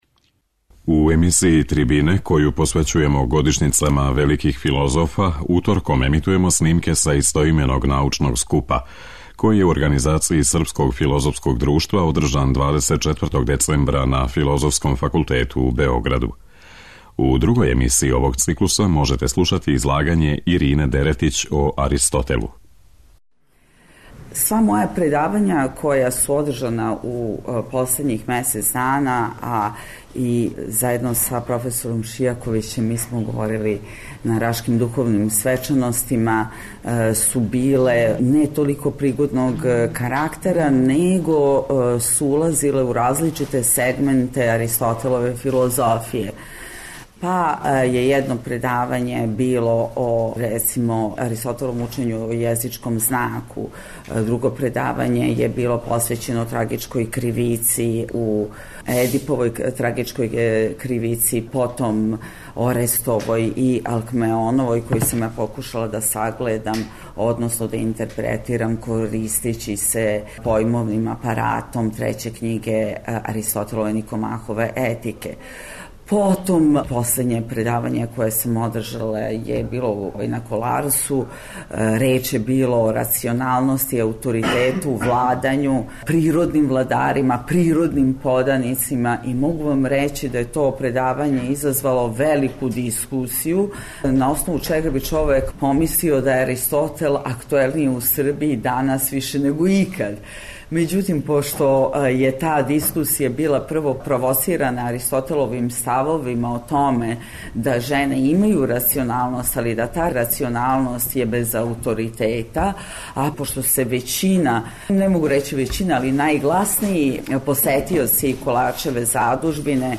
Научни скуп Годишњице великих филозофа Српско филозофско друштво из Београда организује осми пут.